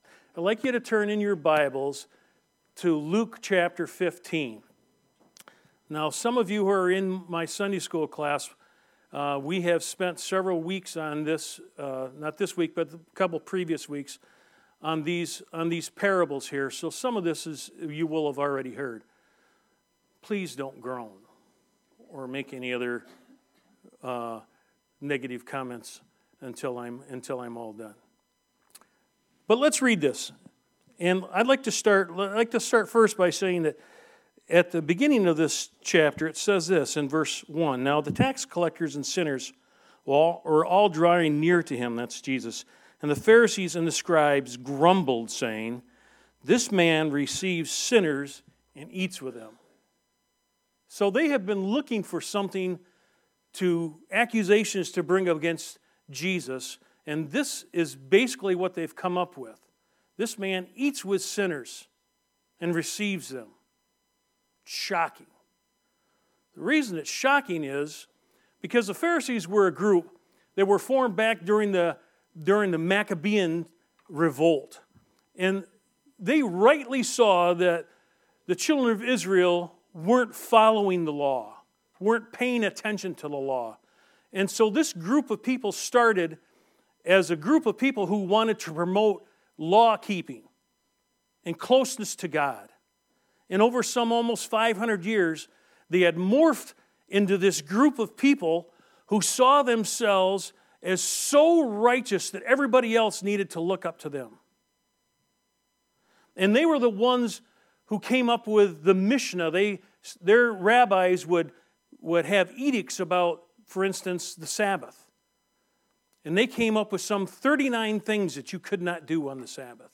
Luke 15:11-32 Service Type: Sunday Morning Bible Text